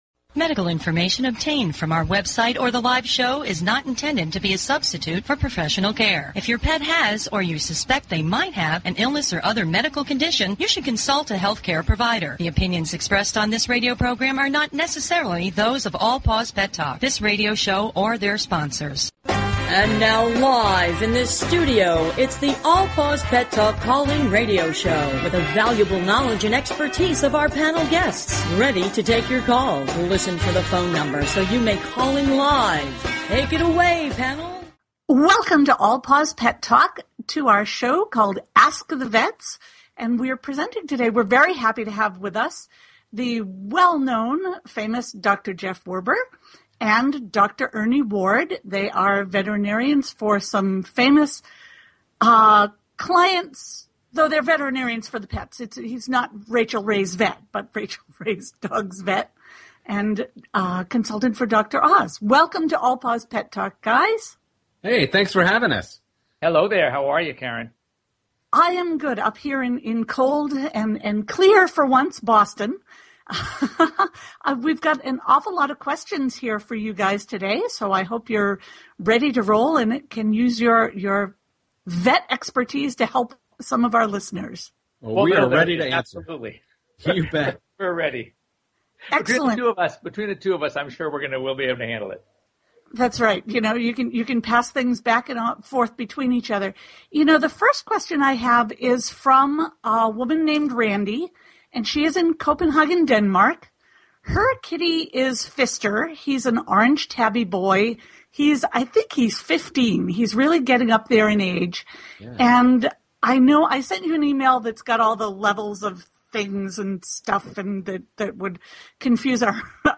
Courtesy of BBS Radio
All Paws Pet Talk is directed to the millions of owners who are devoted to their pets and animals. Our hosts are animal industry professionals covering various specialty topics and giving free pet behavior and medical advice. We give listeners the opportunity to speak with animal experts one on one.